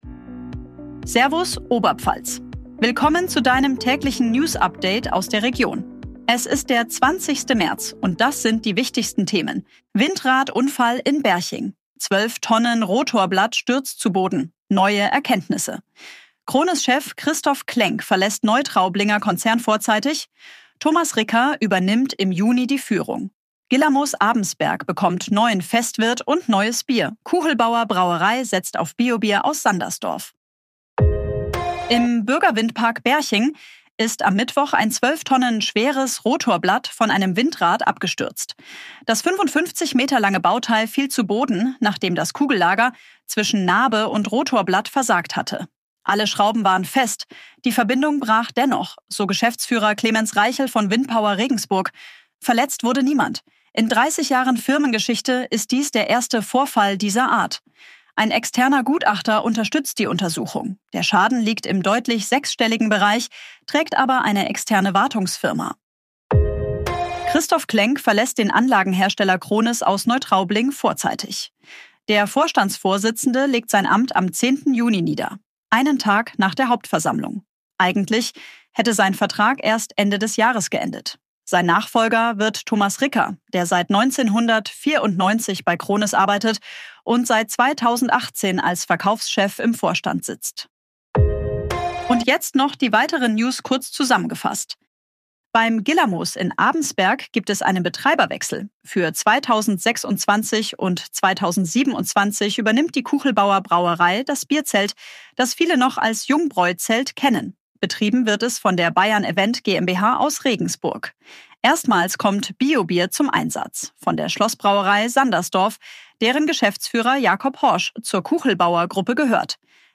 Tägliche Nachrichten aus deiner Region